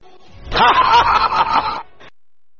Tiếng cười Hahaha...
Thể loại: Câu nói Viral Việt Nam
meme sound effect – hiệu ứng tiếng cười viral cực mạnh, thường được các streamer, YouTuber sử dụng để tạo điểm nhấn gây cười.